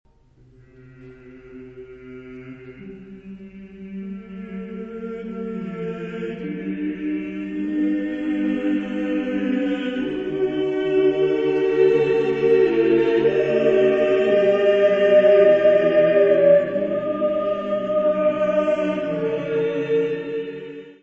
Music Category/Genre:  Classical Music
for sopranos, tenors, baritones, basses and organ